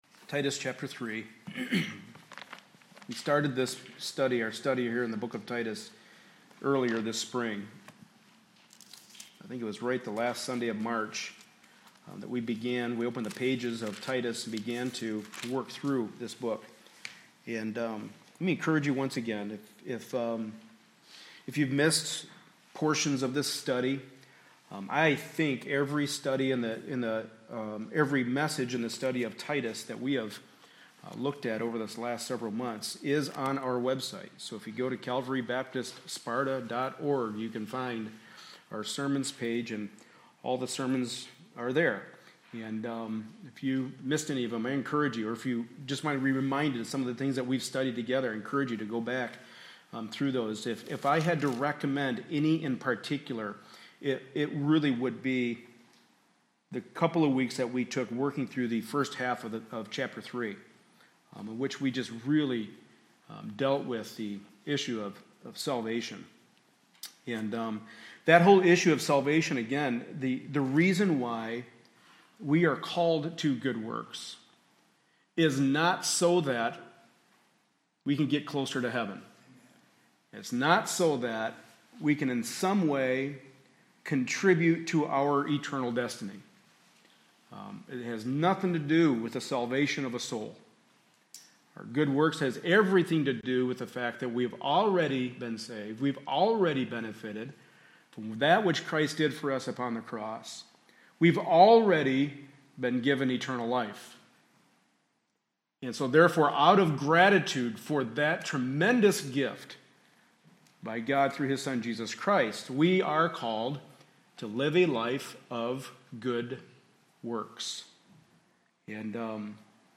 Passage: Titus 3:12-15 Service Type: Sunday Morning Service